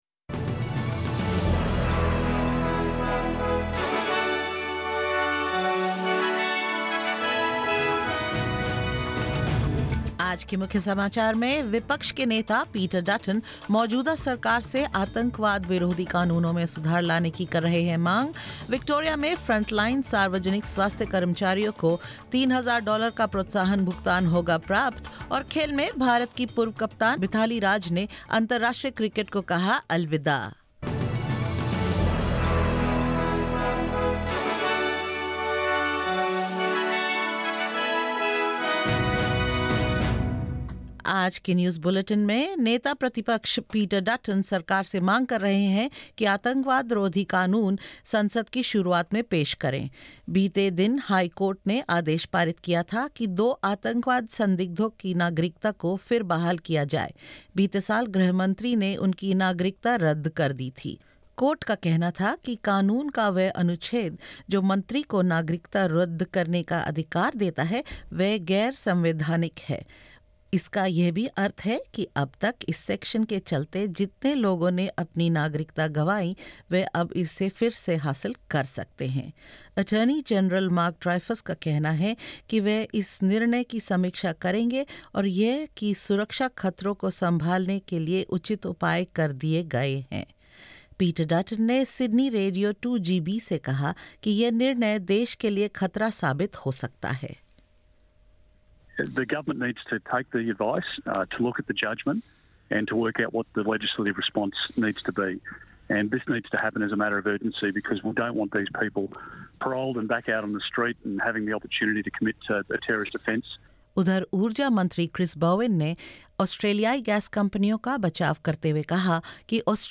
In this latest SBS Hindi bulletin: Opposition leader Peter Dutton urges the government to bring forward the start of parliament to fix anti-terrorism laws; Victoria's frontline public health staff to get $3,000 payments in two installments; In sports, Indian batter Mithali Raj announces her retirement from all forms of international cricket and more.